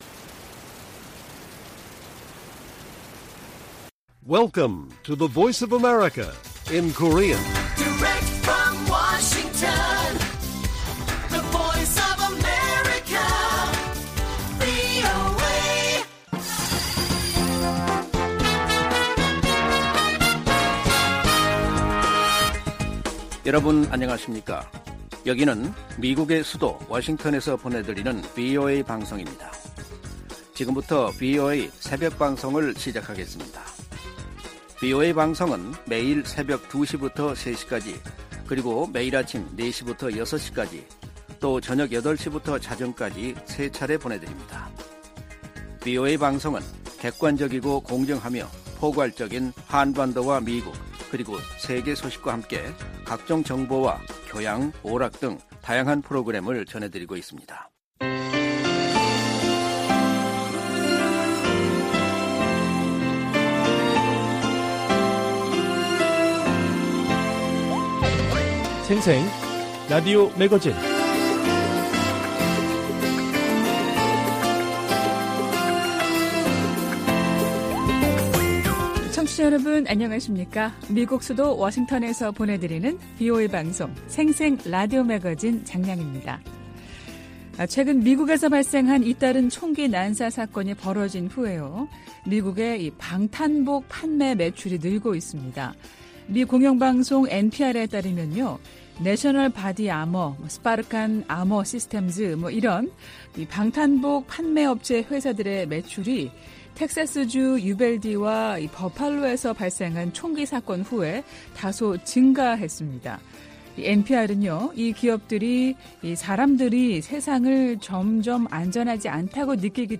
VOA 한국어 방송의 월요일 새벽 방송입니다.